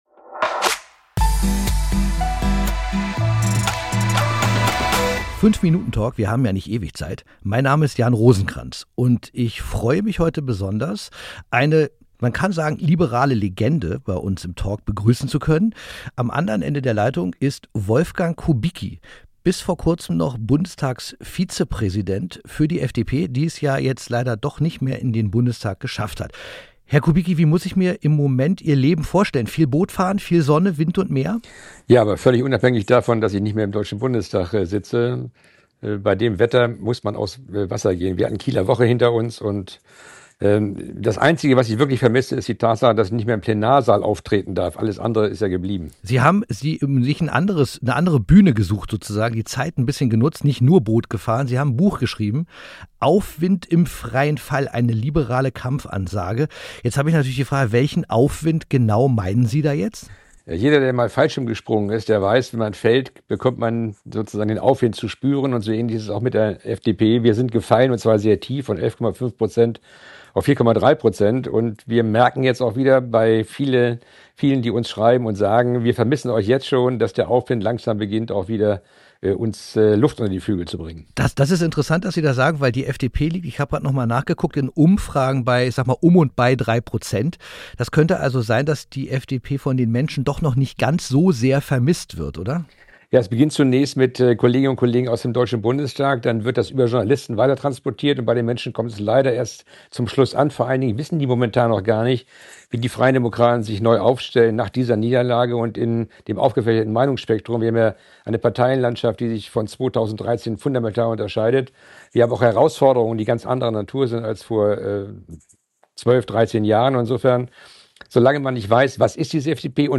Talk